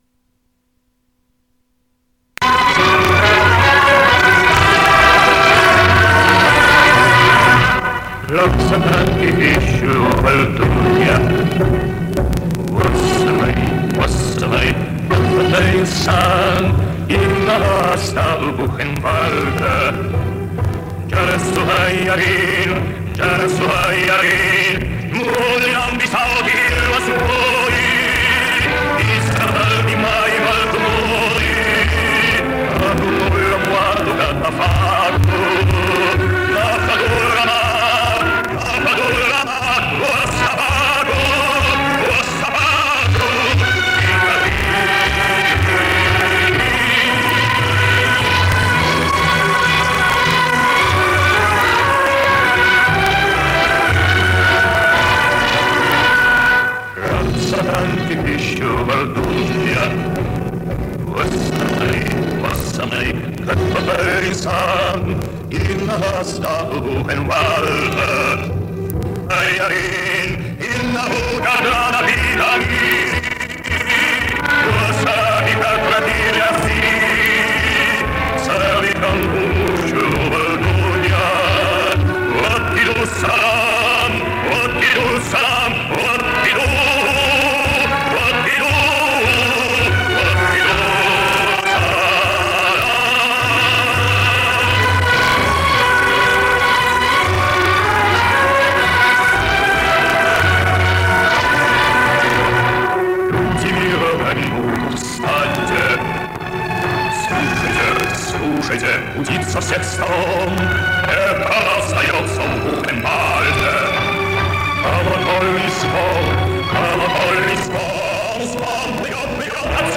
Вот полностью и побыстрее.